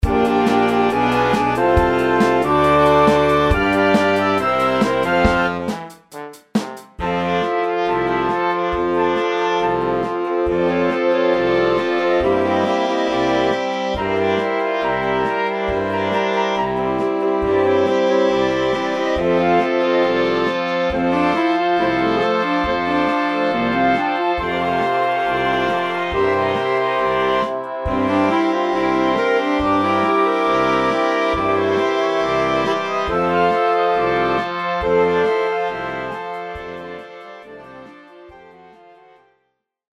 Orkiestrowa , Rozrywkowa